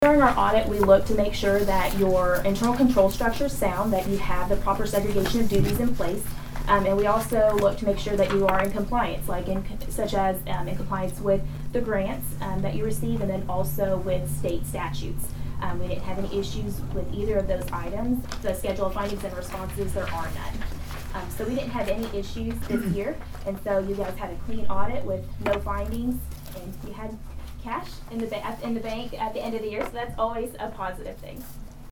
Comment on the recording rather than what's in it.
The Pawhuska City Council got a clean audit report at Tuesday evening's city council meeting.